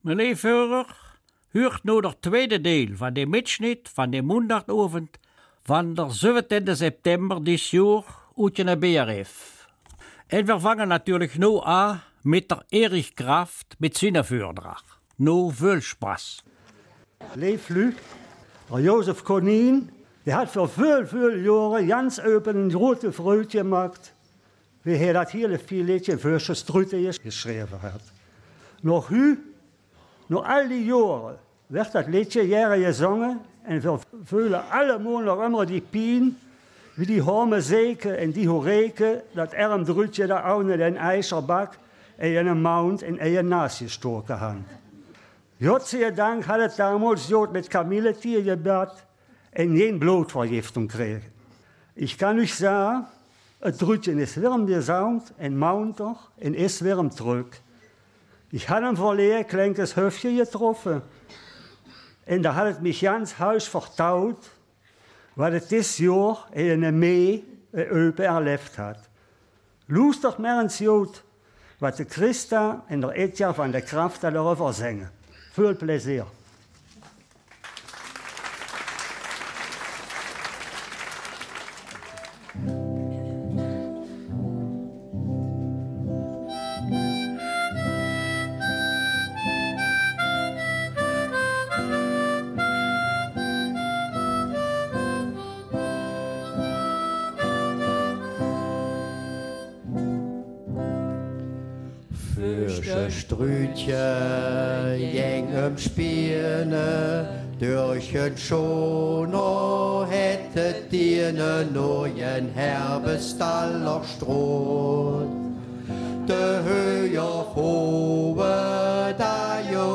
BRF2-Mitschnitt der Eupener Mundartveranstaltung am 11.11.
die am 17. September mit über 300 Besuchern für ein voll besetztes Funkhaus sorgte.
Der zweistündige Mitschnitt ist zu hören am Mittwoch, dem 11. November, von 14 bis 16 Uhr und in der Wiederholung ab 19 Uhr auf BRF2.